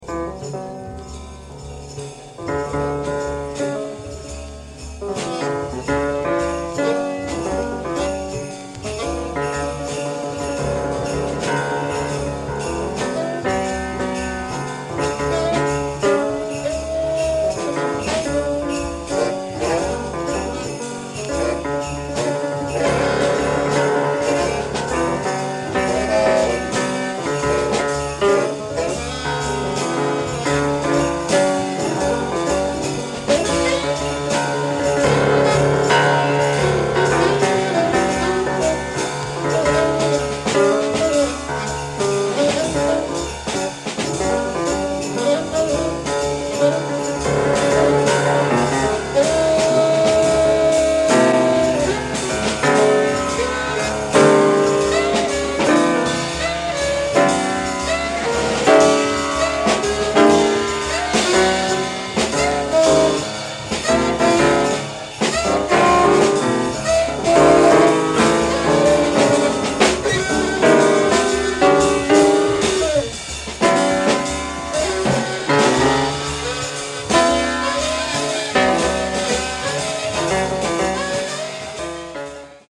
avant-garde workout